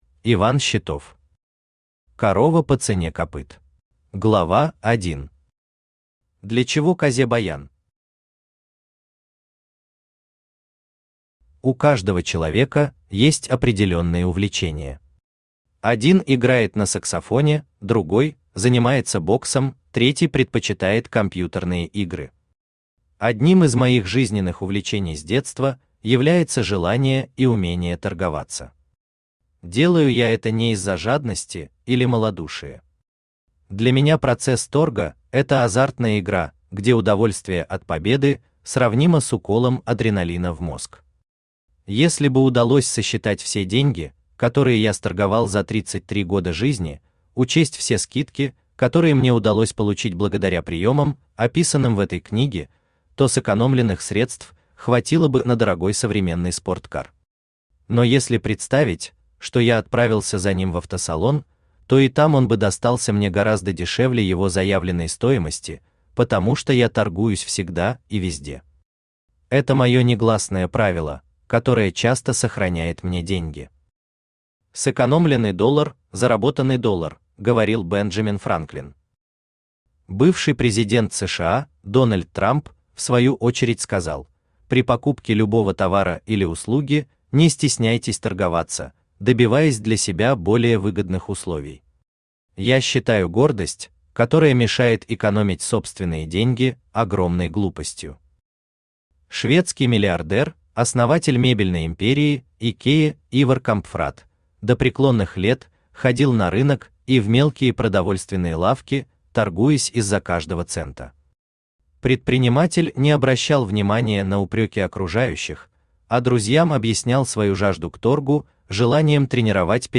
Аудиокнига Корова по цене копыт | Библиотека аудиокниг
Aудиокнига Корова по цене копыт Автор Иван Григорьевич Щитов Читает аудиокнигу Авточтец ЛитРес.